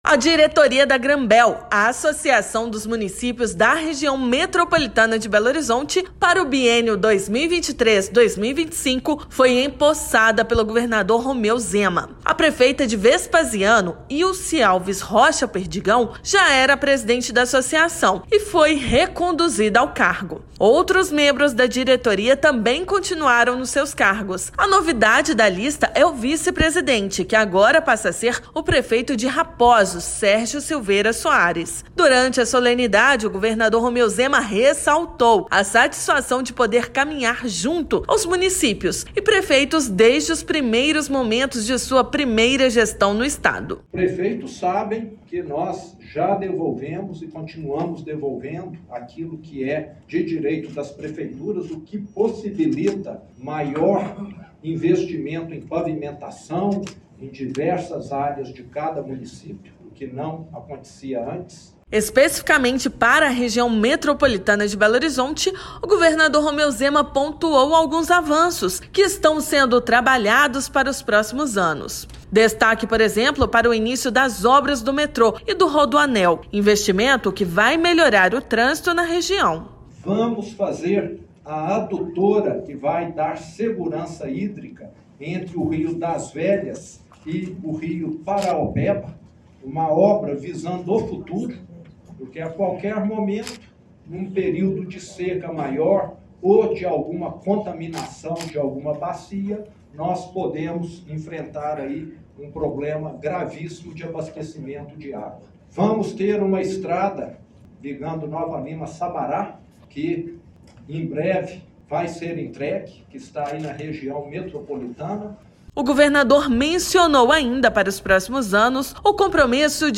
[RÁDIO] Governador enfatiza parceria com os municípios em posse da diretoria da Granbel